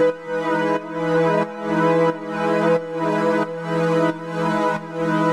GnS_Pad-alesis1:4_90-E.wav